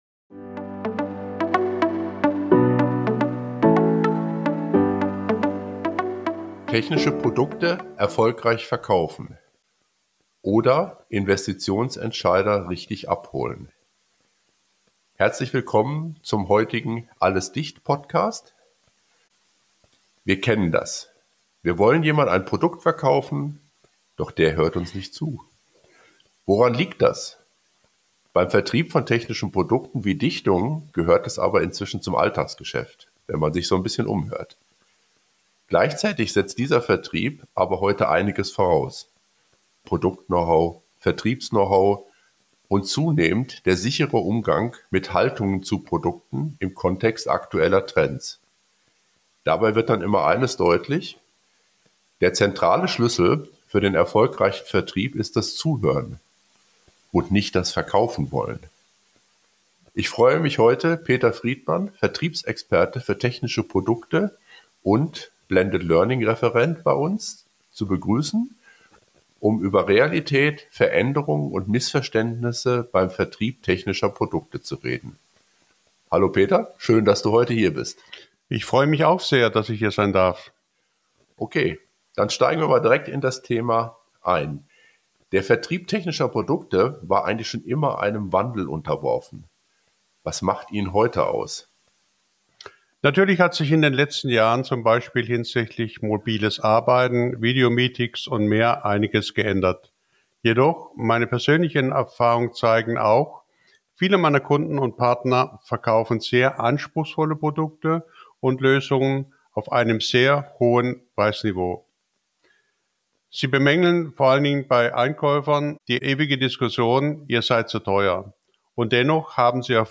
Das Gespräch